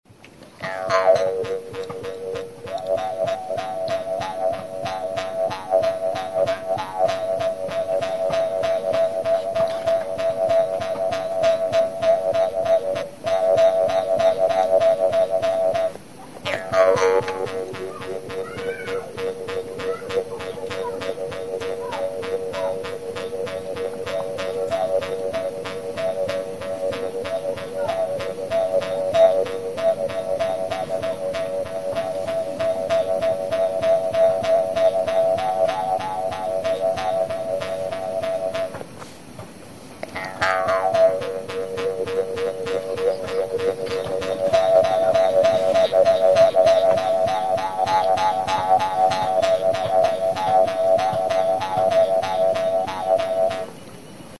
Idiophones -> Plucked / flexible -> Without sound board
SUSAP; Jew's harp
Banbuzko tablatxo borobildua da.
CANE; BAMBOO